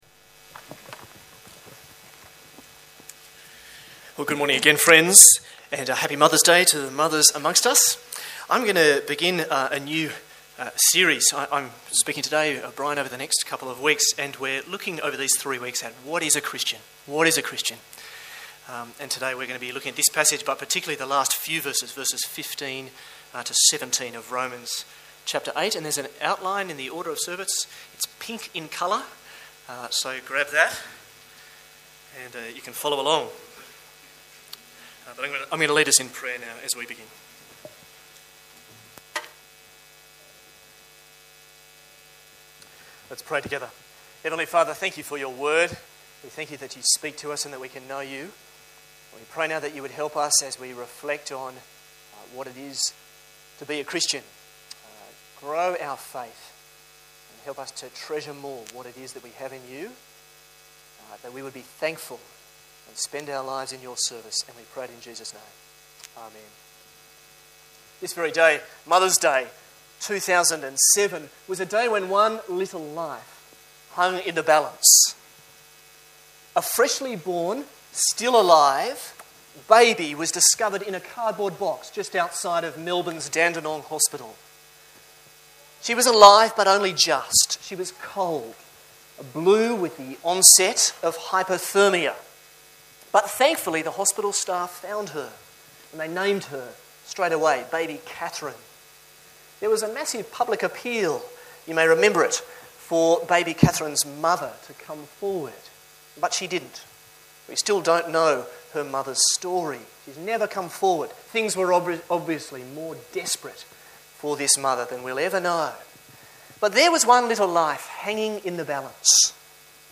View Sermon details and listen